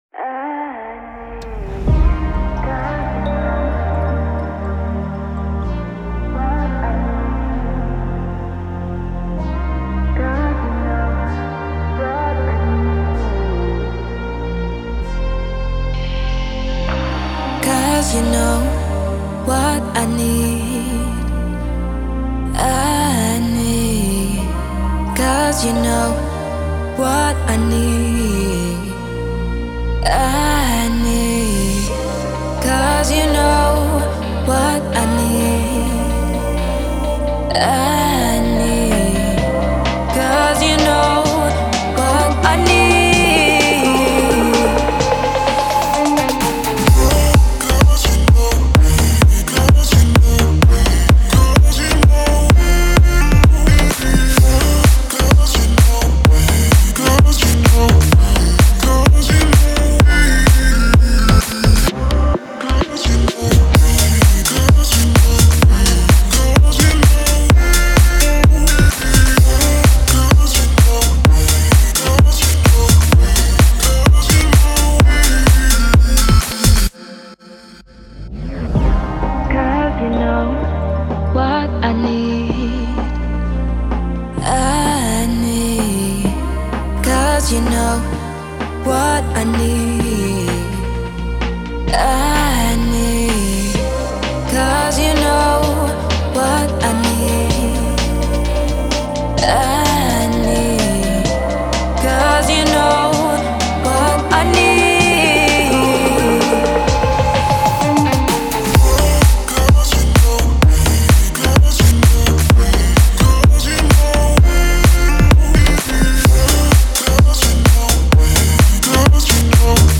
это зажигательный трек в жанре поп-EDM